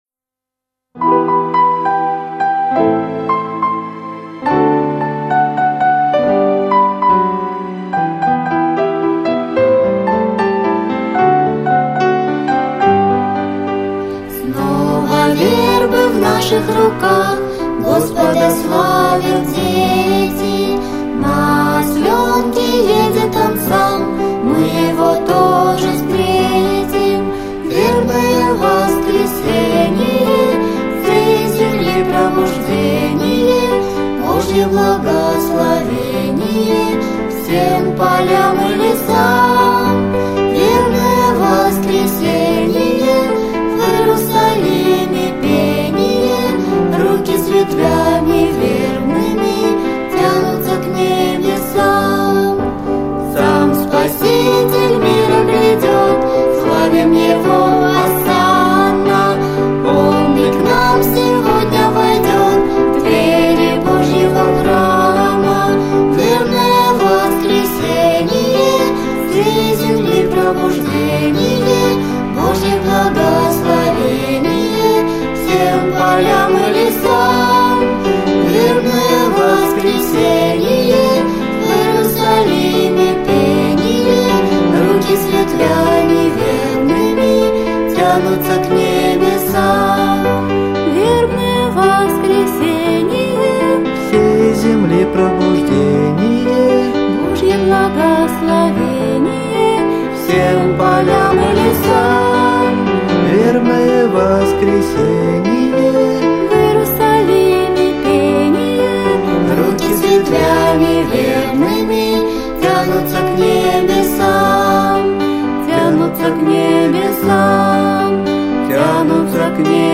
детской песни
фонограмму (плюс)
Рубрика Пасхальные песни, Фонограммы песен Метки: , , , , ,